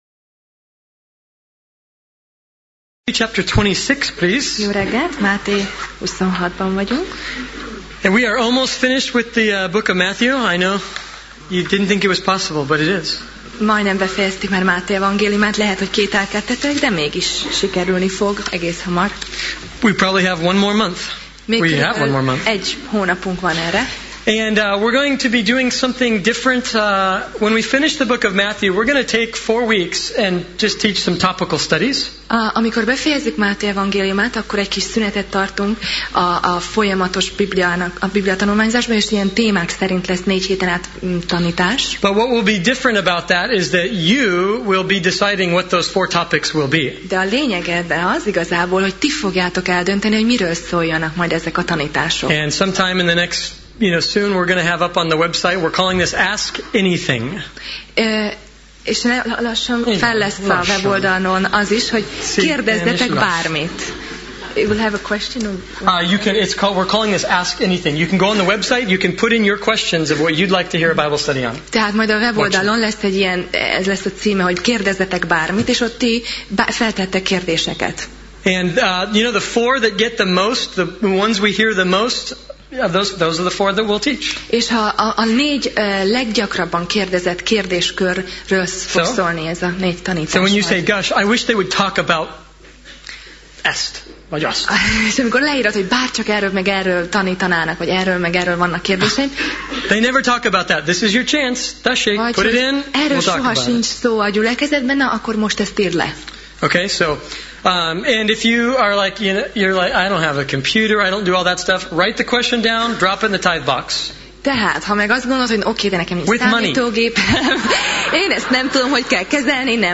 Passage: Máté (Matthew) 26:47–75 Alkalom: Vasárnap Reggel